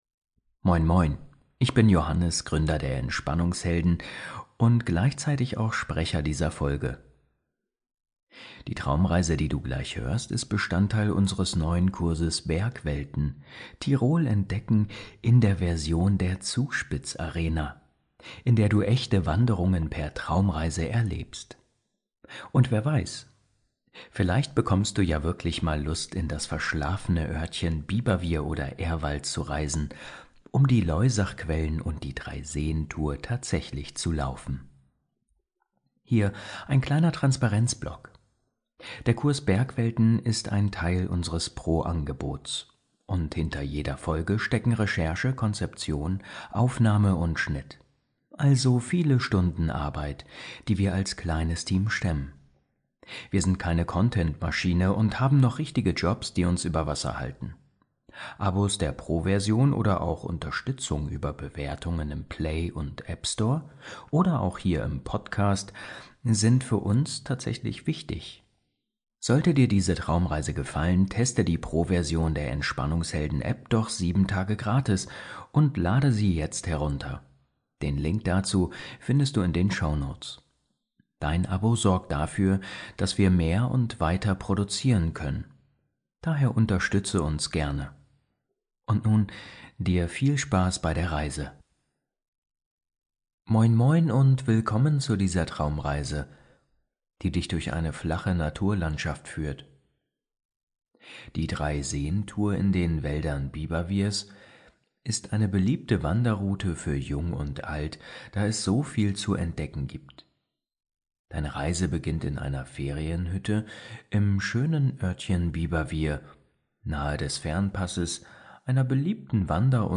Bergwelten - Über die Loisachquellen zum Blindsee ~ Entspannungshelden – Meditationen zum Einschlafen, Traumreisen & Entspannung Podcast